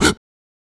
Mouth Interface (11).wav